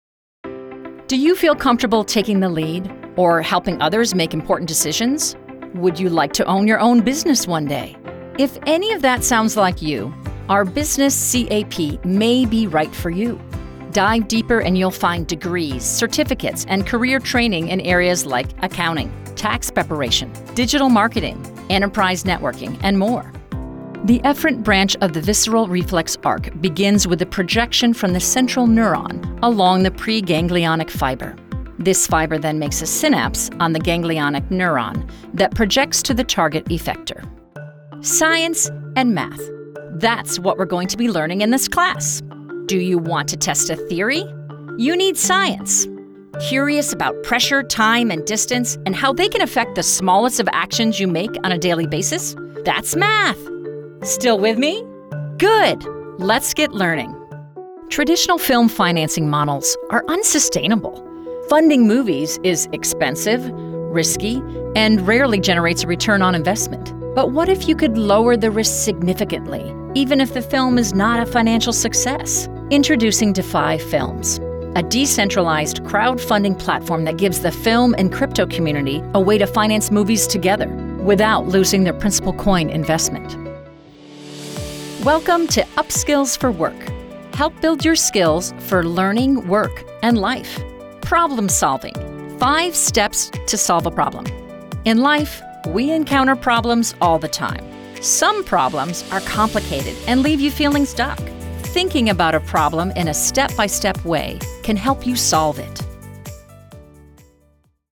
Friendly, conversational and knowledgeable, a team player and a good listener
Commercial Demo
Middle Aged